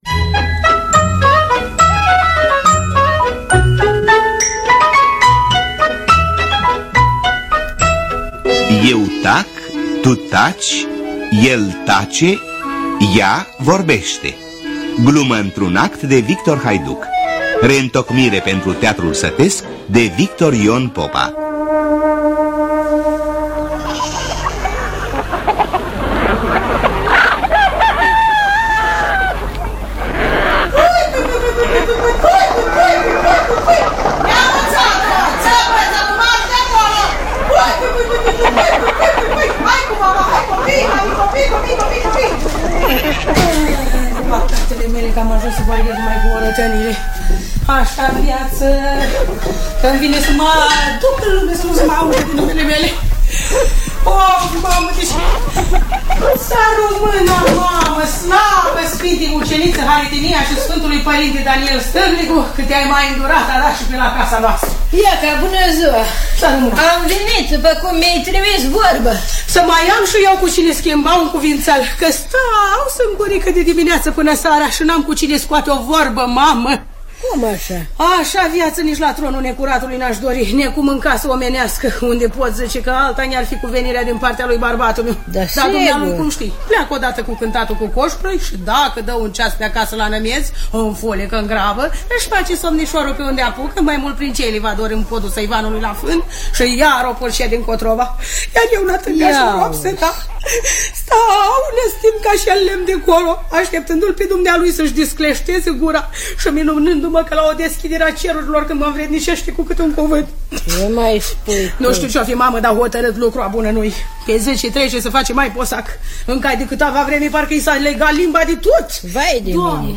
Eu tac, tu taci, el tace, ea… vorbește de Victor Haiduc – Teatru Radiofonic Online
În distribuție: Eugenia Popovici, Draga Olteanu-Matei, Sandu Sticlaru.